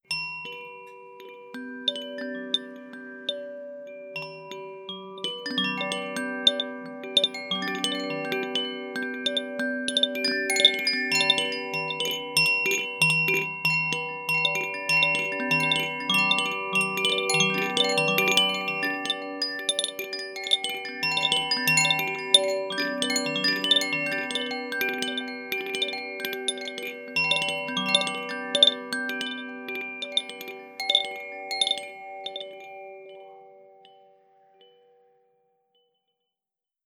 • koshi wind chimes ignis sounds.wav
koshi_wind_chimes_ignis_sounds_oii.wav